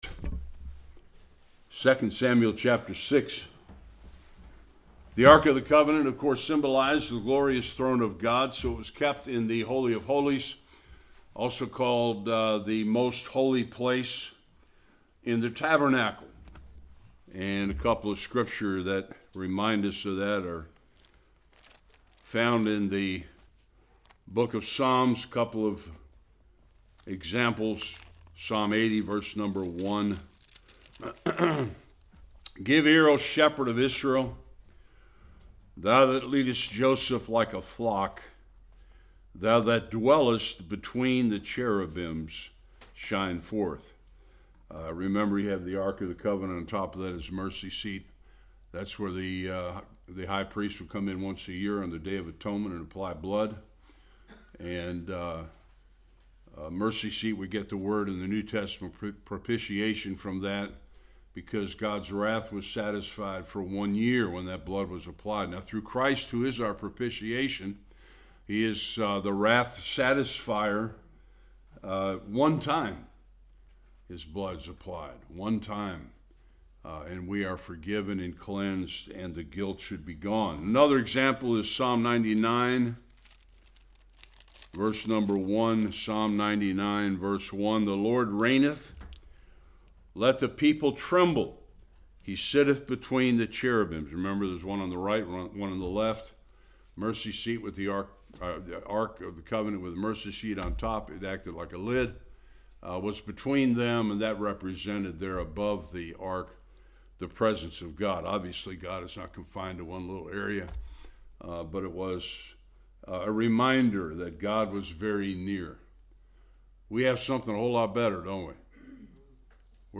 1-23 Service Type: Sunday School David’s first attempt to move the Ark caused the death of 2 men-why?